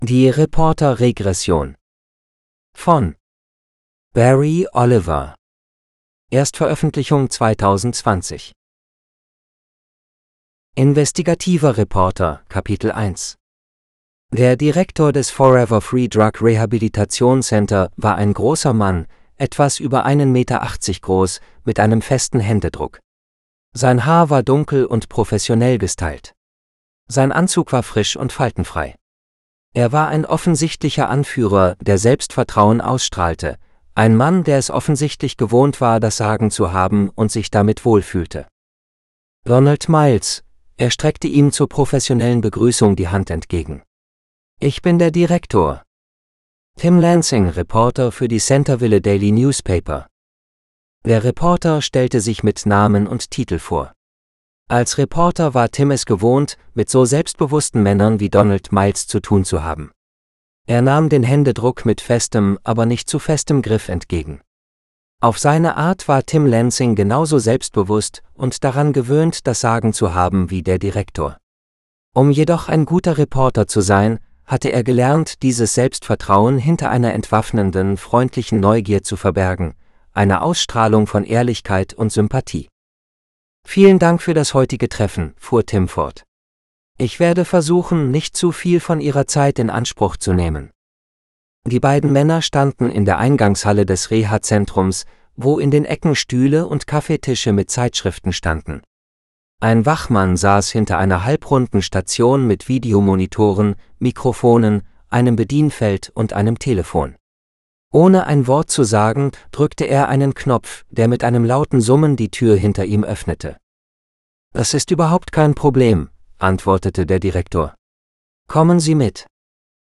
The Reporter Regression GERMAN GERMAN (AUDIOBOOK – female): $US5.75